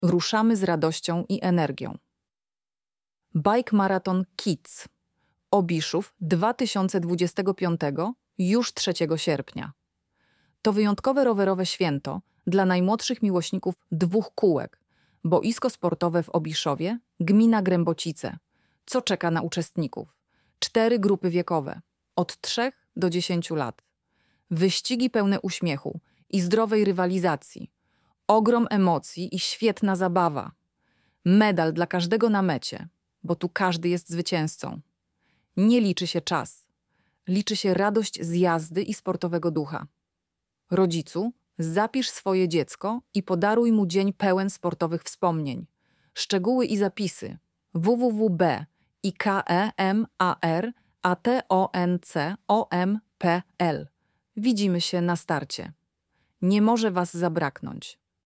lektor-bike-maraton.mp3